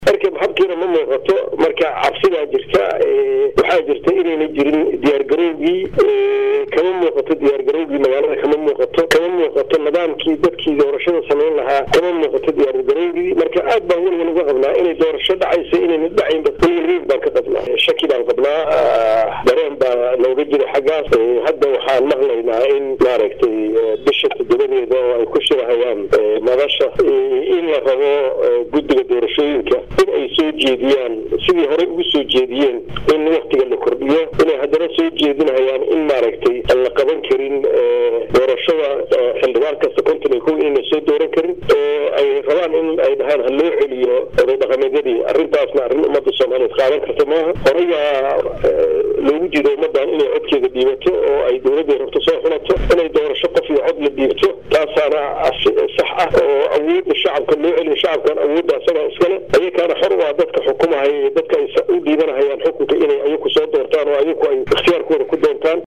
Maxamuud Axmed Nuur Tarsan oo hadlay warbaahinta ayaa Walwal ka muujiyay Qabsoomida Doorashooyinka Dadban ee Dabayaaqada Sanadkaan lagu wado inay soomaaliya ka dhacaan.